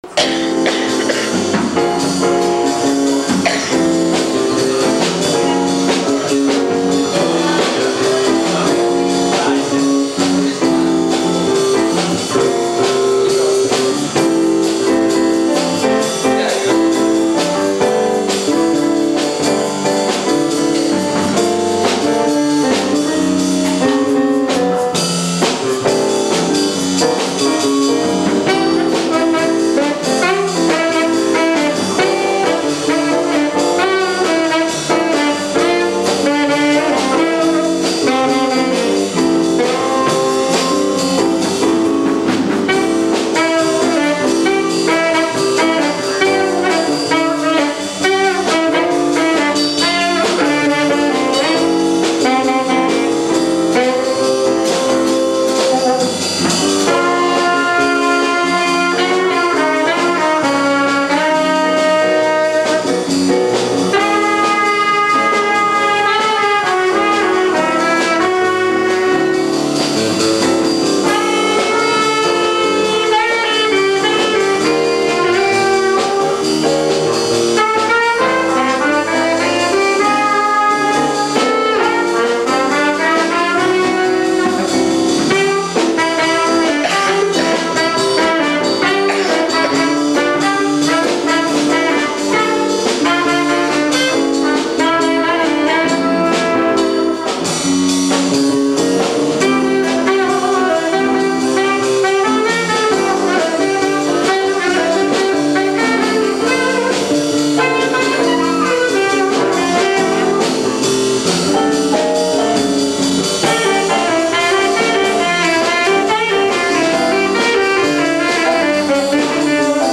tenor sax
trumpet
keyboards
bass
drums